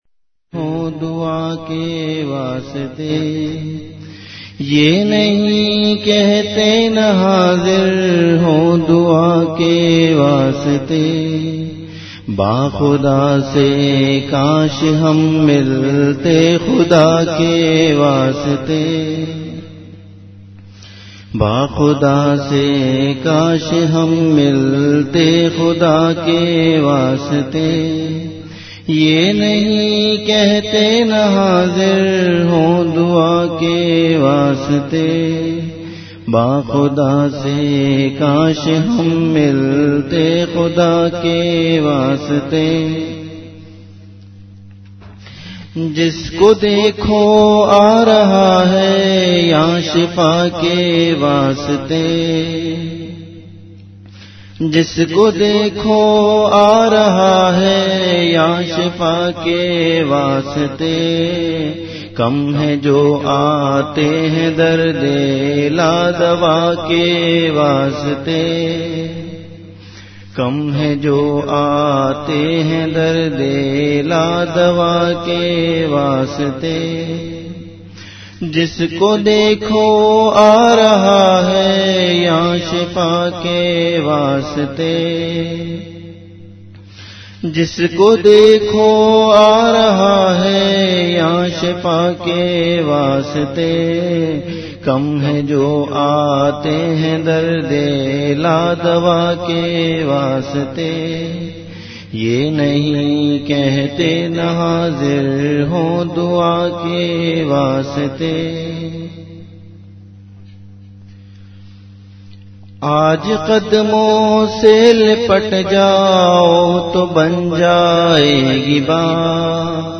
Category Majlis-e-Zikr
Event / Time After Isha Prayer